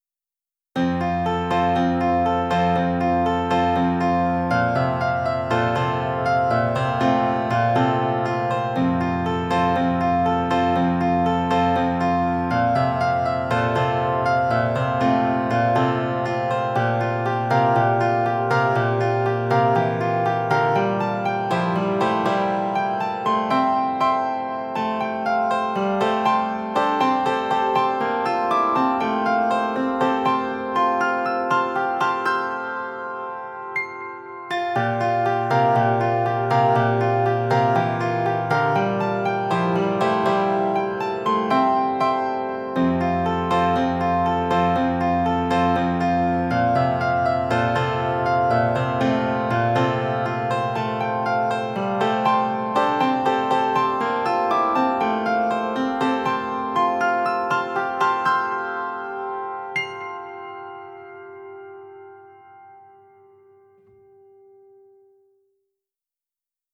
PIANO ABC (33)